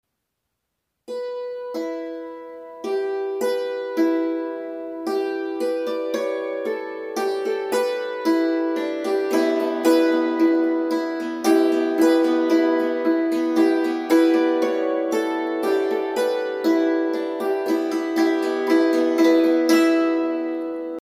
Dulcimer